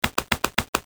cartoon33.mp3